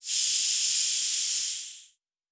Air Hiss 1_05.wav